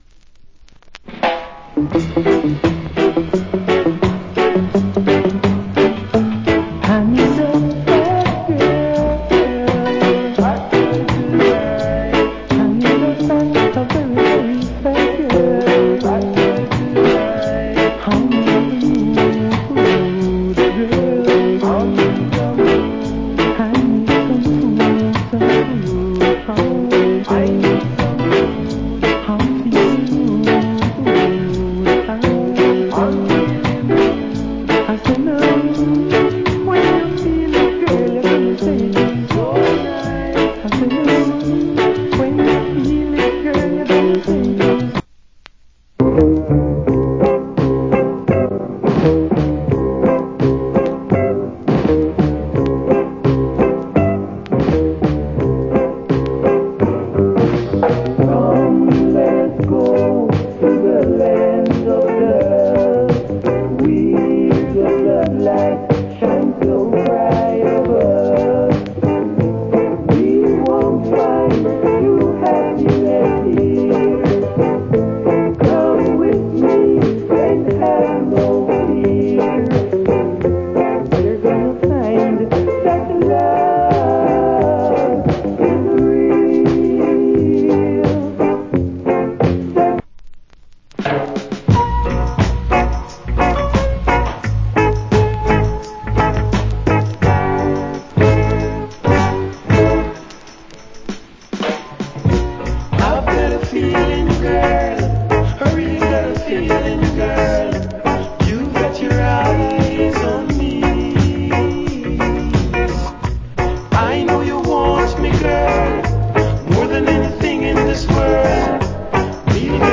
Foundation Great Rock Steady.